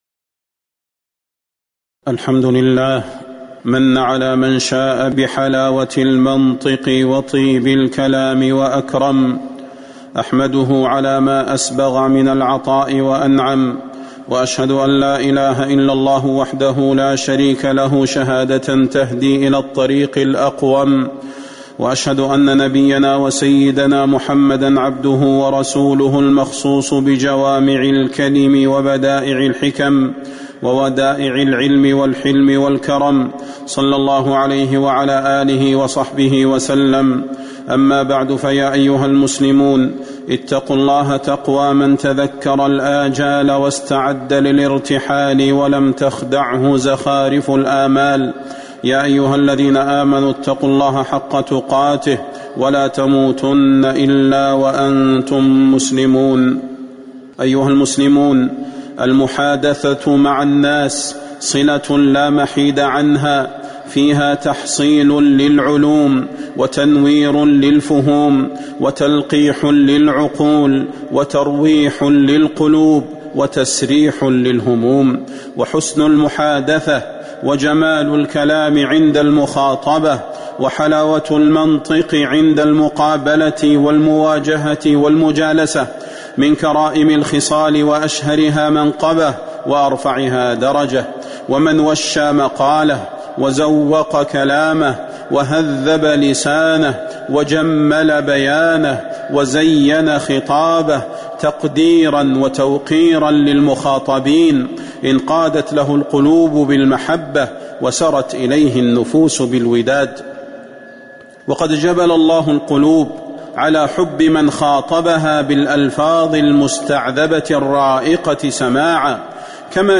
فضيلة الشيخ د. صلاح بن محمد البدير
تاريخ النشر ٢٧ صفر ١٤٤٤ هـ المكان: المسجد النبوي الشيخ: فضيلة الشيخ د. صلاح بن محمد البدير فضيلة الشيخ د. صلاح بن محمد البدير حسن المخاطبة